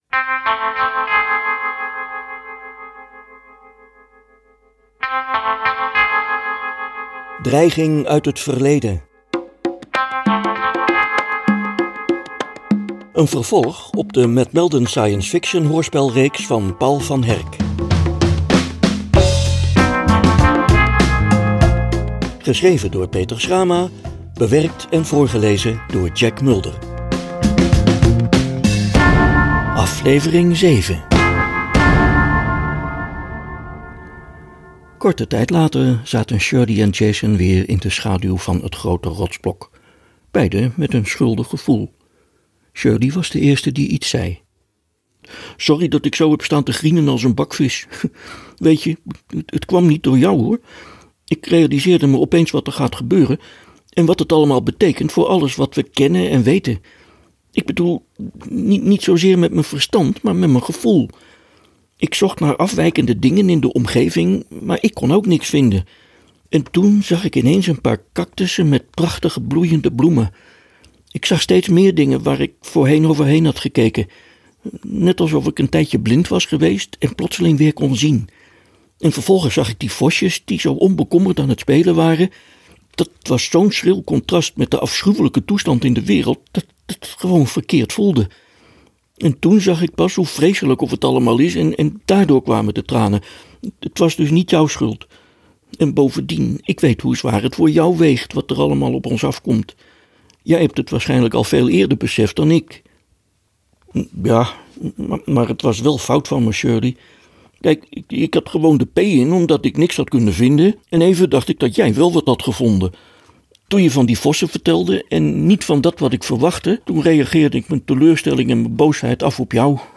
Een 10-delig luisterboek dat een vervolg is op de Matt Meldon Science Fiction hoorspelreeks van Paul van Herck.